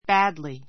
badly A2 bǽdli バ ド り 副詞 比較級 worse wə́ː r s ワ ～ ス 最上級 worst wə́ː r st ワ ～ スト ❶ 悪く; 下手に He did badly in the English test.